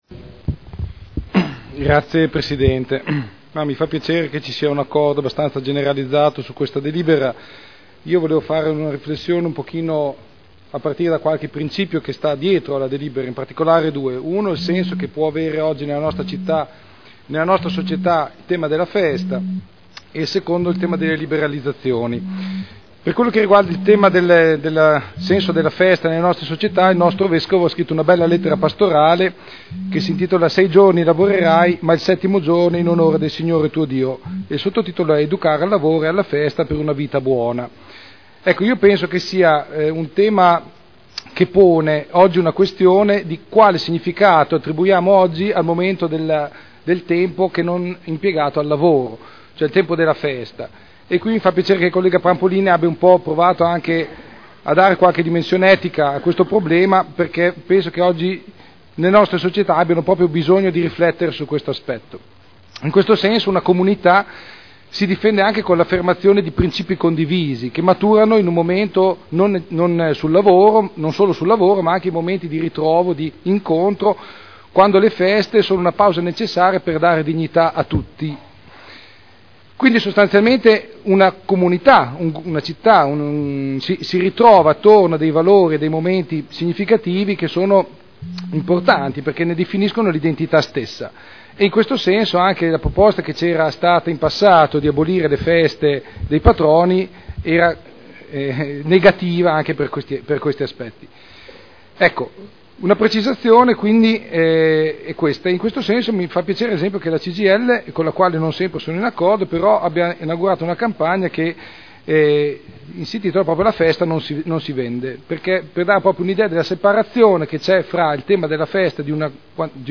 Enrico Artioli — Sito Audio Consiglio Comunale
Dibattito.